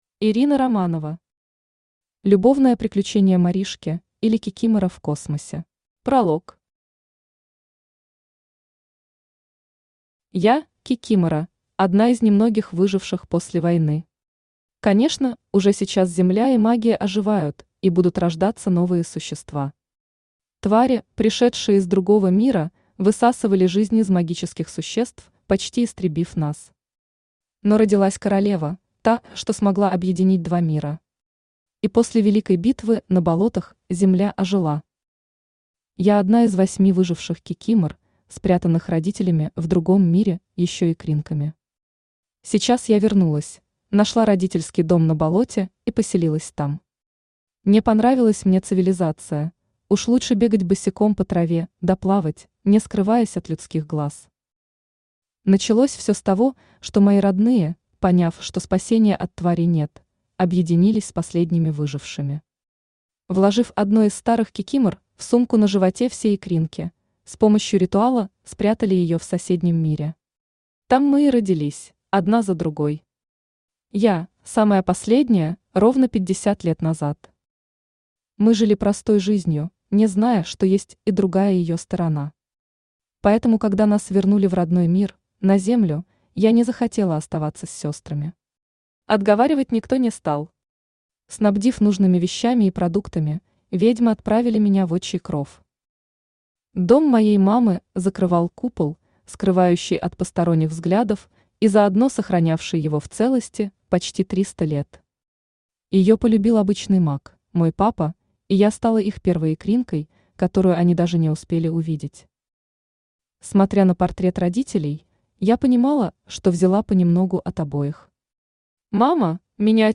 Аудиокнига Любовное приключение Маришки, или Кикимора в космосе | Библиотека аудиокниг
Aудиокнига Любовное приключение Маришки, или Кикимора в космосе Автор Ирина Романова Читает аудиокнигу Авточтец ЛитРес.